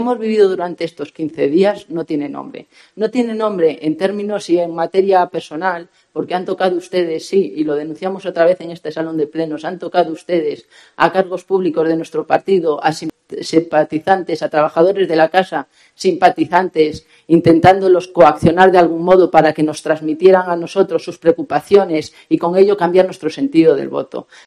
Eva Arias, portavoz del PSOE, en el Pleno de Ordenanzas